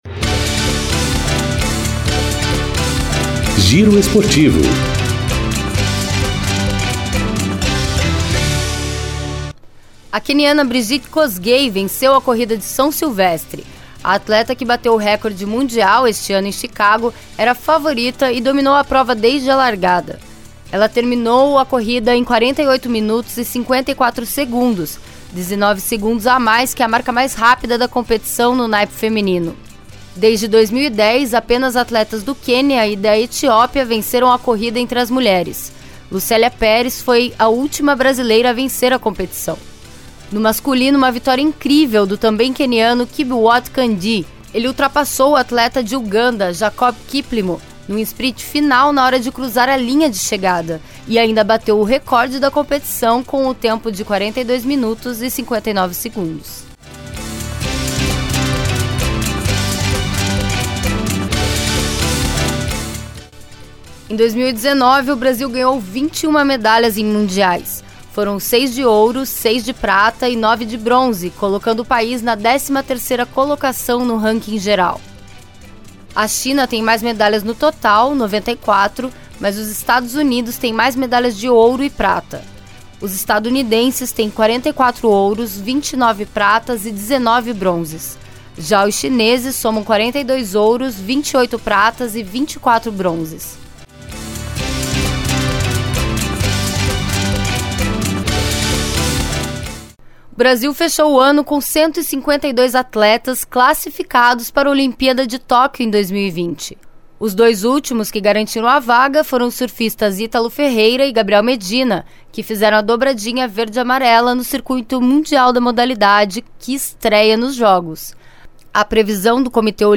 Giro Esportivo COM TRILHA